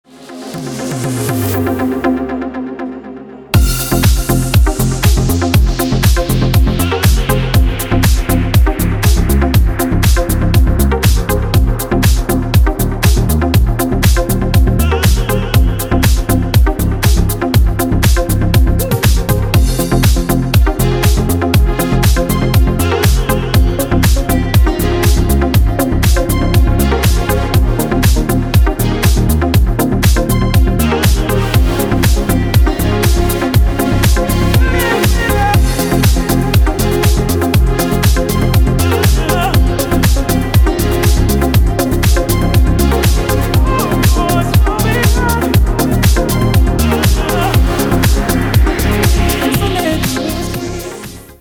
• Качество: 320, Stereo
remix
deep house
dance
club
Стиль: deep house.